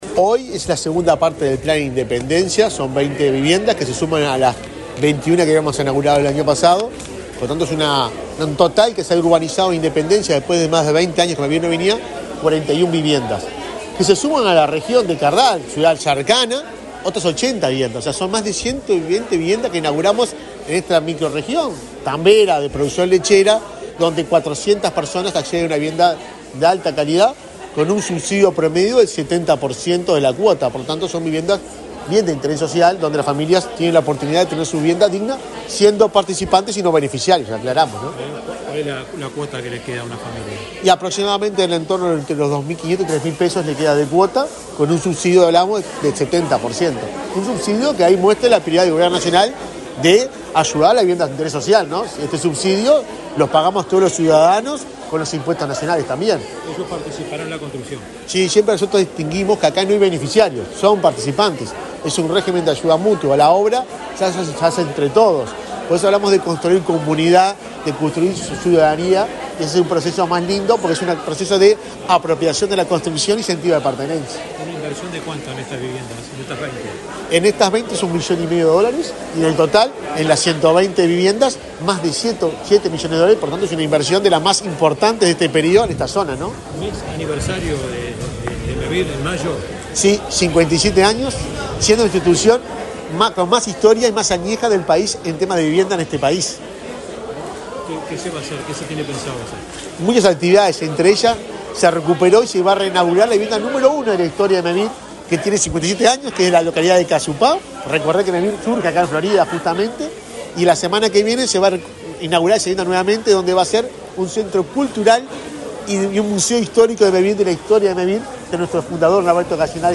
Declaraciones del presidente de Mevir, Juan Pablo Delgado
Declaraciones del presidente de Mevir, Juan Pablo Delgado 07/05/2024 Compartir Facebook X Copiar enlace WhatsApp LinkedIn El presidente de Mevir, Juan Pablo Delgado, dialogó con la prensa en Independencia, departamento de Florida, antes de participar en la inauguración de un plan de viviendas de ese organismo, en la referida localidad.